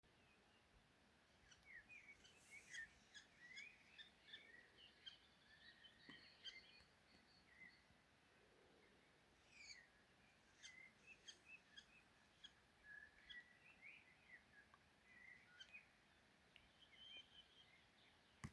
Brūnā čakste, Lanius collurio